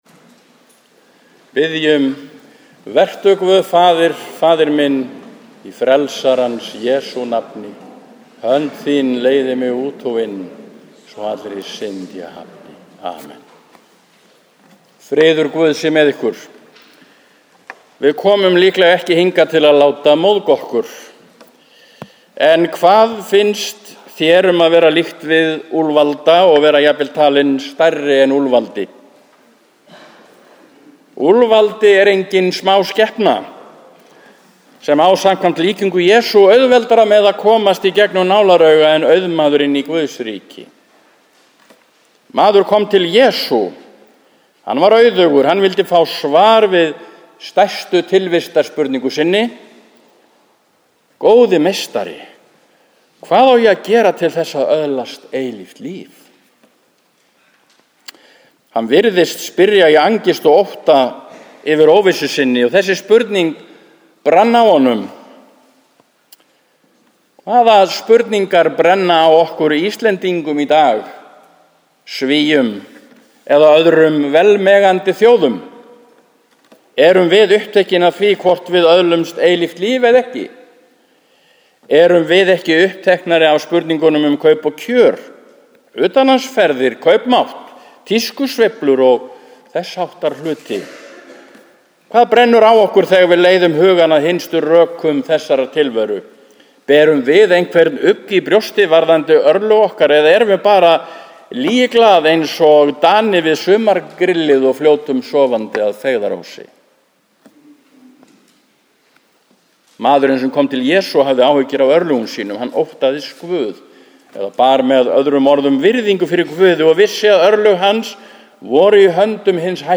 Prédikun í Neskirkju 29. september 2013 – 18 sd. e. trinitatis